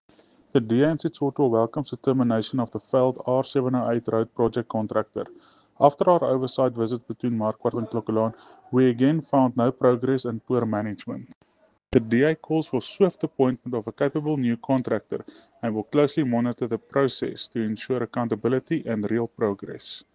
Afrikaans soundbites by Cllr Jose Coetzee and Sesotho soundbite by Cllr Kabelo Moreeng.